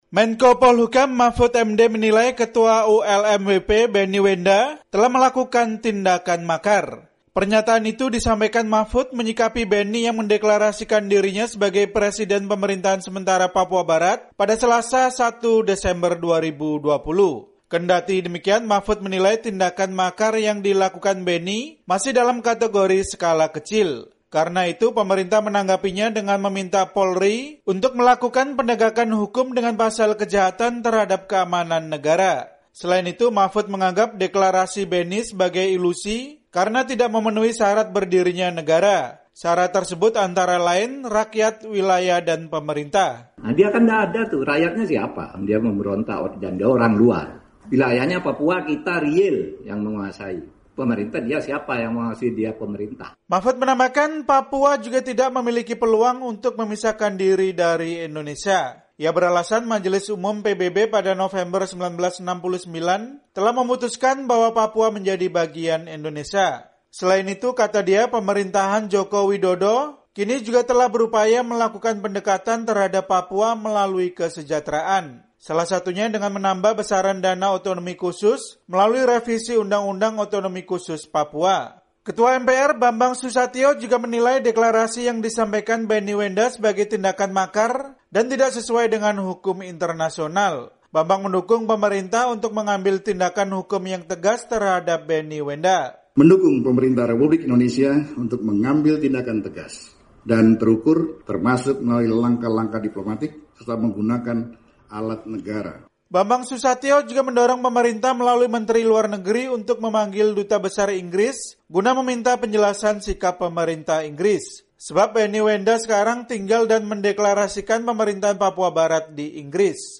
"Apa ada pemerintahnya? Dia tidak ada, rakyatnya siapa? Wilayah Papua kita yang kuasai. Orang Papua sendiri tidak juga mengakui," jelas Mahfud Md saat memberikan keterangan pers di Jakarta, Kamis (3/12/2020).